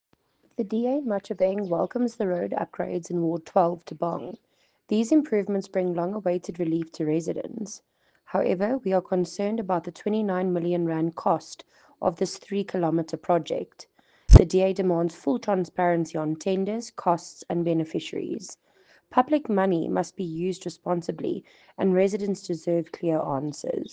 English soundbite by Cllr Abigail Schoeman,